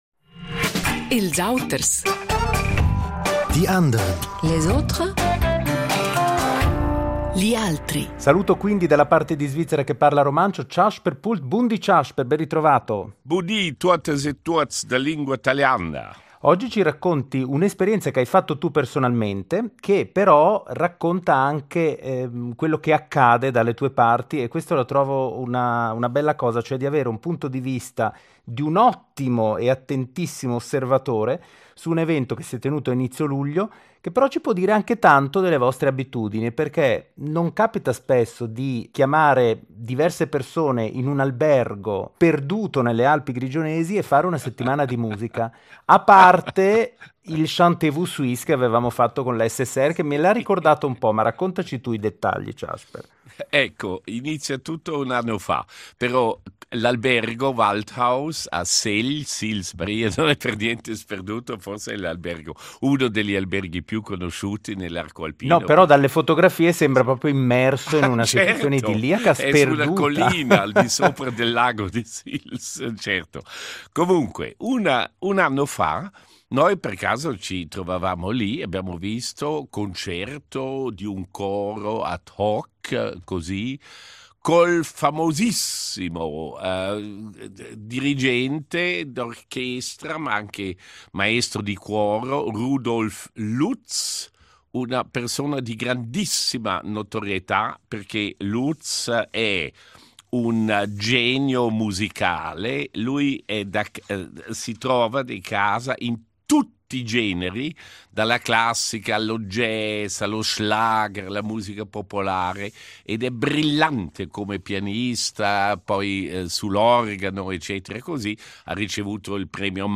Musica, cori e paesaggi alpini: un’esperienza intensa tra professionisti e appassionati, guidati dal maestro